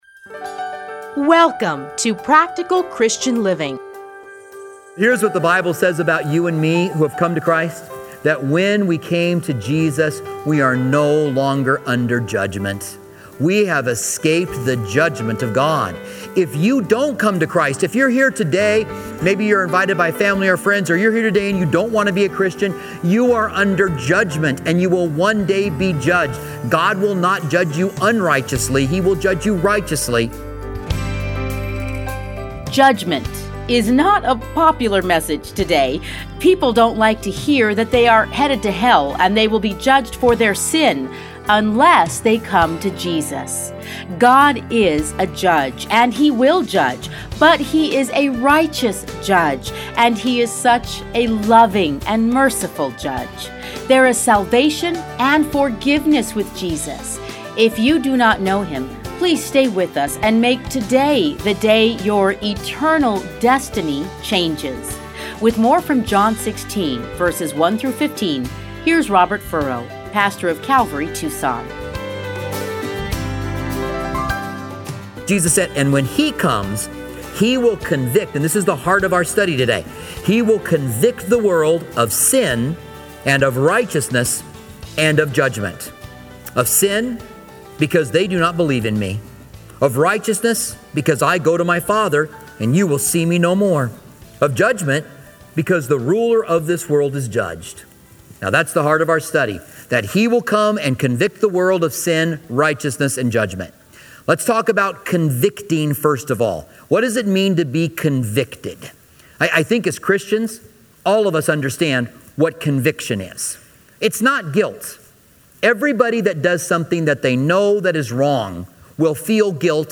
radio programs